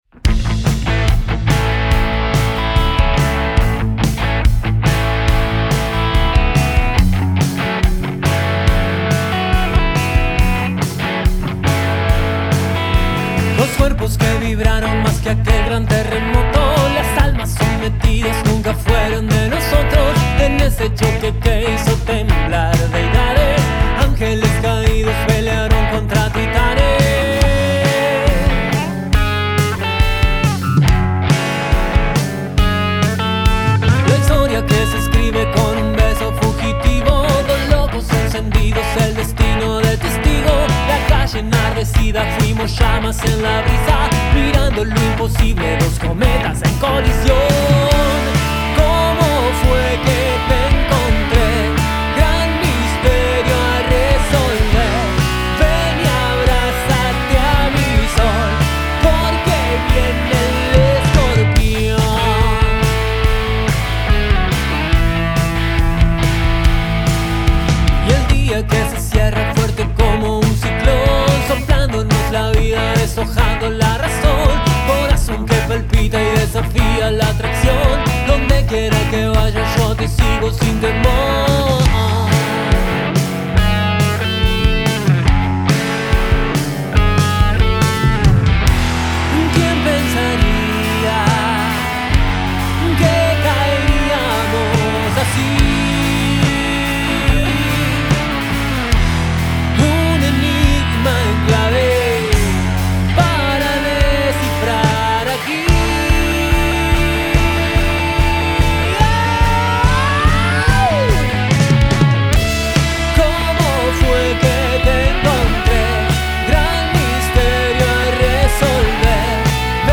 Grabada en vivo el 1 de junio de 2025
en Estudio del Monte